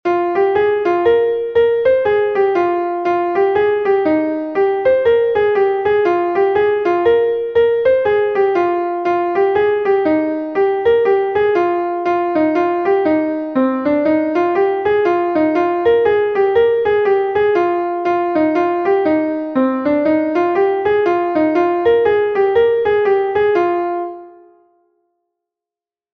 Take 6 ridées I is a Laridé from Brittany recorded 1 times by Skeduz